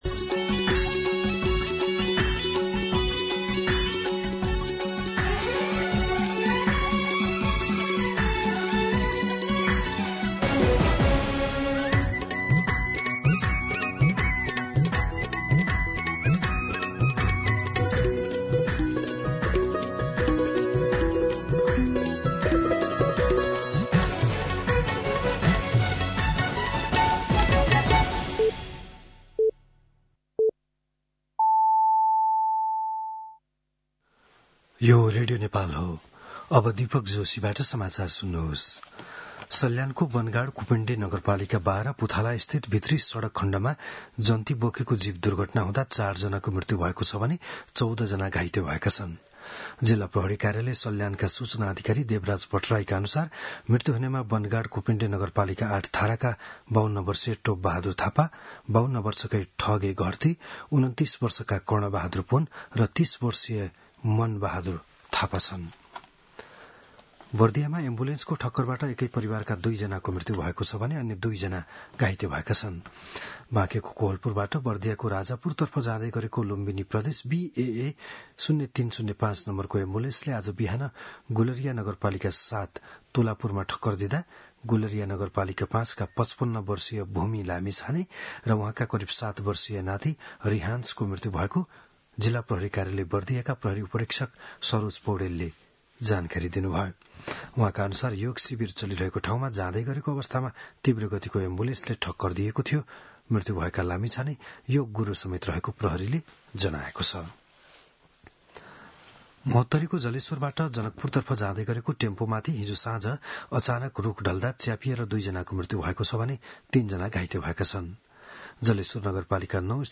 बिहान ११ बजेको नेपाली समाचार : ६ वैशाख , २०८३